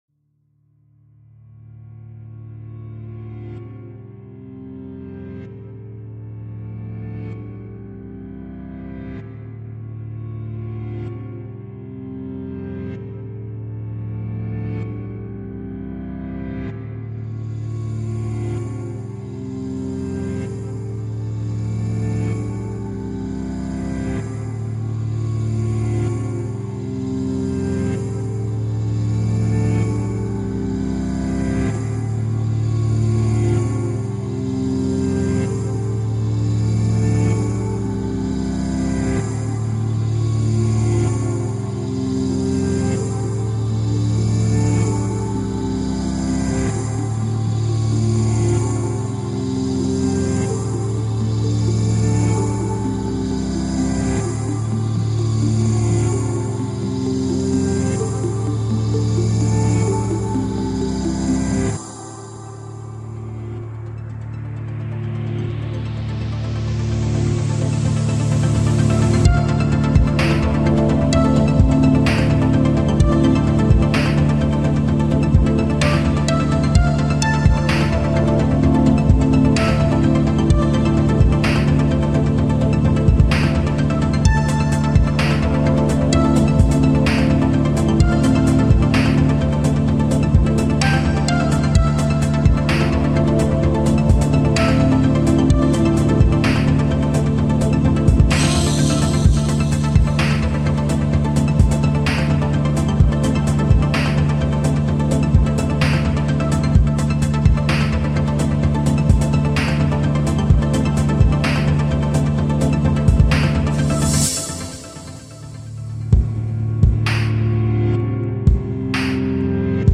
This is my first attempt at Ambient as a genre.